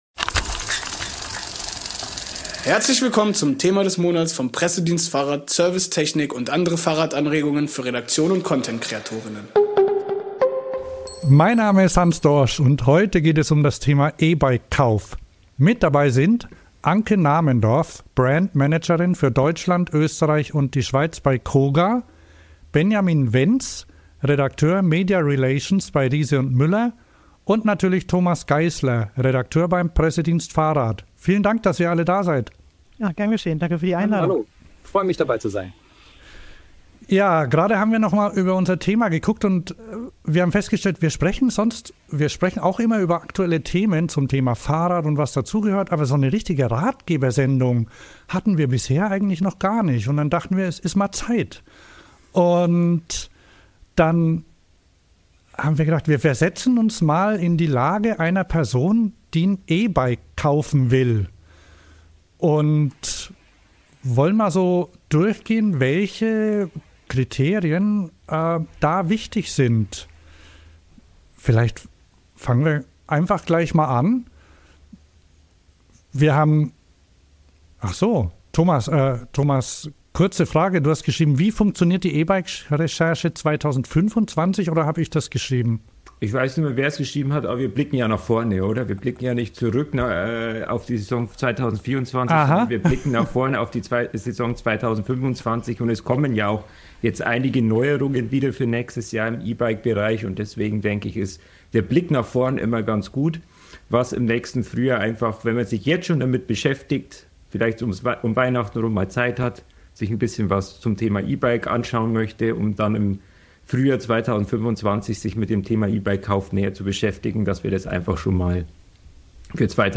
In dieser Folge bieten wir mal eine richtige Ratgebersendung an. Wir versetzen uns in die Perspektive einer Person, die ein E-Bike kaufen möchte, und besprechen Schritt für Schritt die wichtigsten Aspekte im Kaufprozess: von der Recherche über die Ausstattung bis zum richtigen Kaufzeitpunkt.